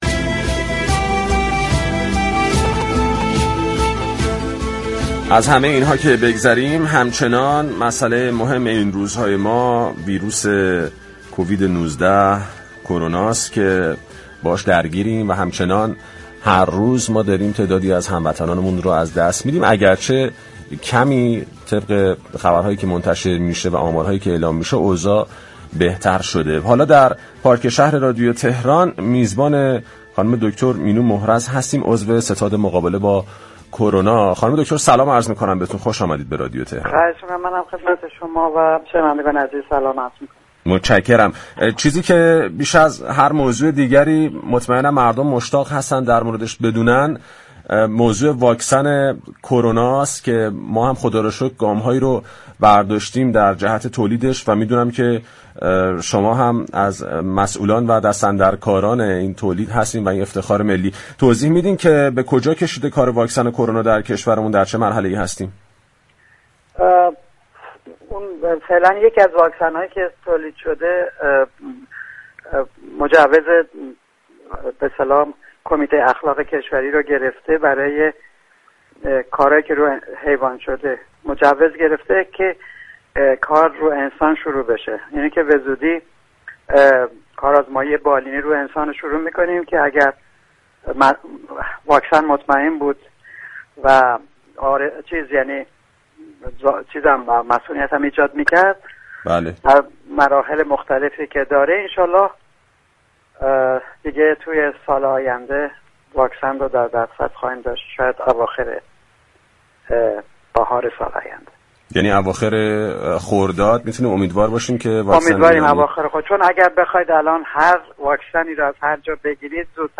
به گزارش پایگاه اطلاع رسانی رادیو تهران، دكتر مینو محرز در گفتگو با برنامه پارك شهر گفت: یكی از واكسن های كرونای تولیدی در ایران، مجوز كمیته كشوری اخلاق را دریافت كرده و نتایج آن در تست حیوانی مطلوب بوده است.